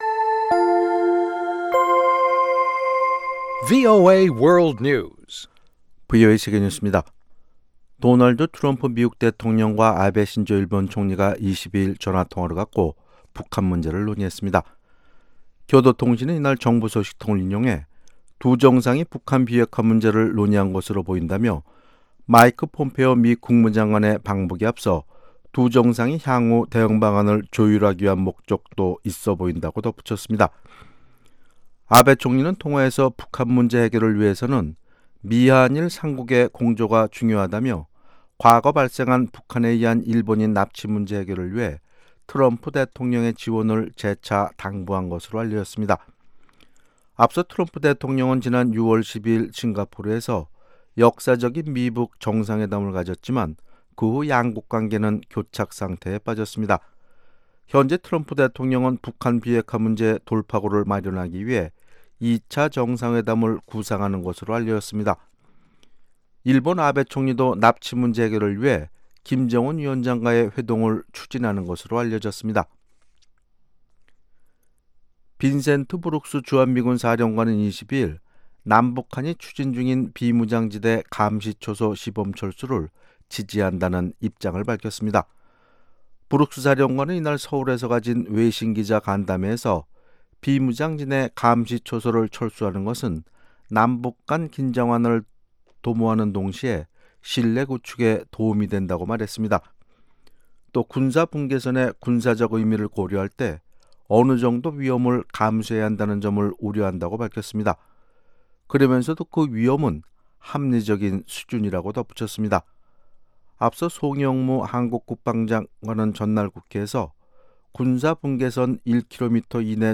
VOA 한국어 아침 뉴스 프로그램 '워싱턴 뉴스 광장' 2018년 8월 23일 방송입니다. 빈센트 브룩스 미한연합사령관은 비무장지대에서 감시초소를 철수하는 것이 긴장 완화 신뢰 구축에 도움이 될 것이라고 밝혔습니다. 심각한 제재를 받고 있는 북한이 사이버 활동을 통해 미국과 한국에 대한 정보를 수집하고 공격에 나설 수 있다고 미 정보당국 고위 관계자가 지적했습니다.